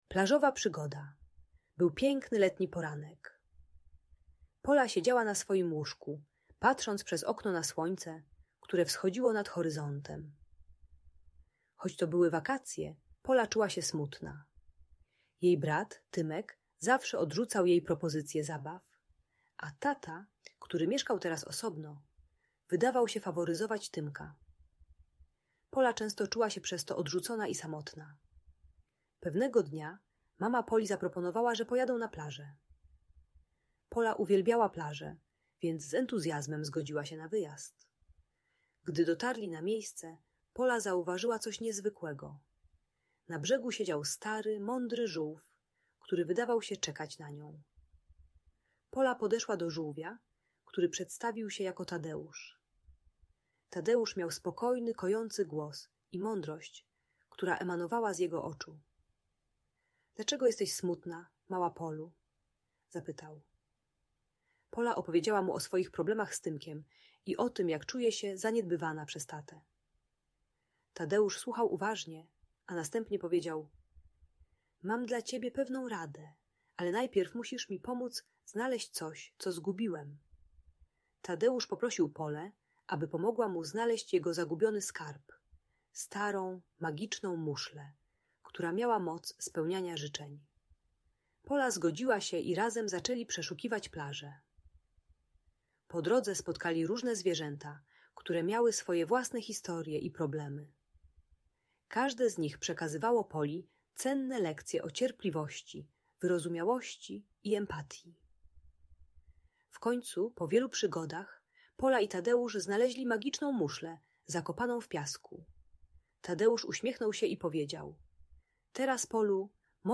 Bajka dla dziecka które czuje się odrzucone przez rodzeństwo i tatę po rozwodzie rodziców. Audiobook dla dzieci w wieku 5-8 lat o rywalizacji między rodzeństwem i poczuciu faworyzowania. Uczy cierpliwości, empatii i techniki wytrwałego budowania relacji zamiast wycofywania się w złości.